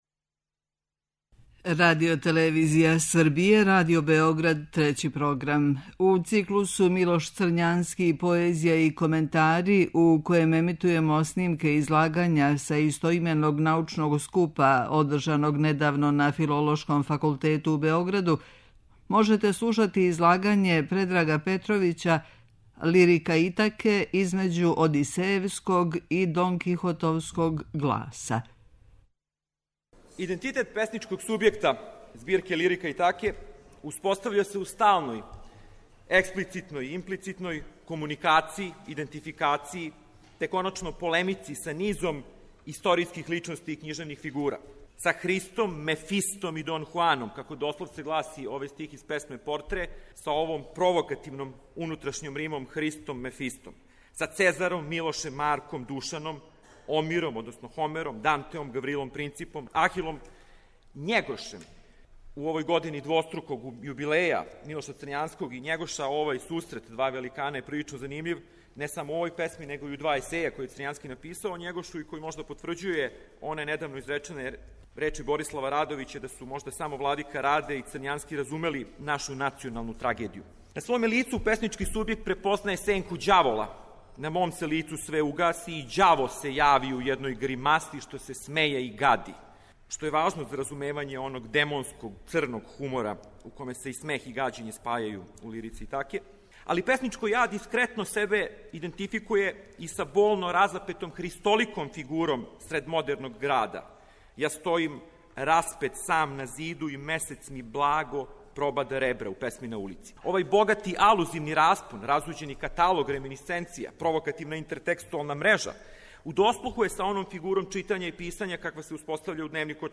У циклусу МИЛОШ ЦРЊАНСКИ: ПОЕЗИЈА И КОМЕНТАРИ, у среду 26. фебруара и 5. марта, моћи ћете да пратите снимке излагања са истоименог научног скупа који је крајем прошле године одржан на Филолошком факултету у Београду.